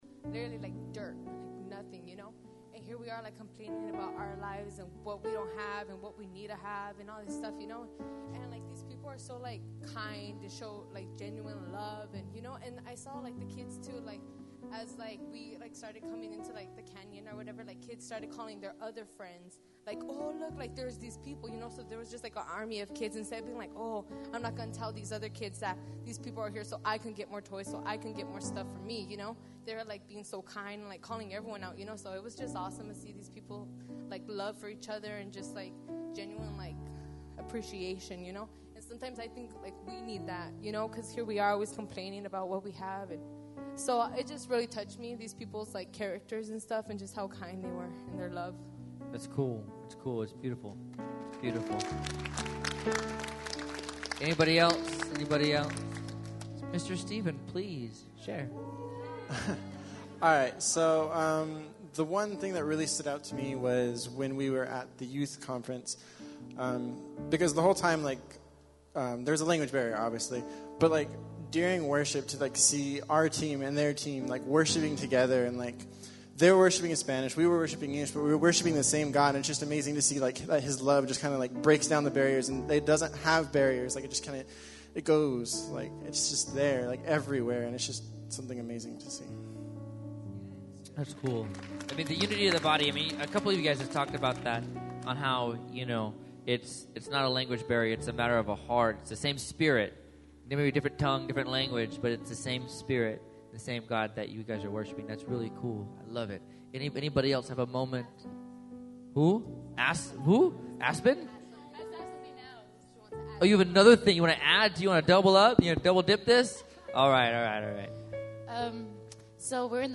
Thankful Worship Night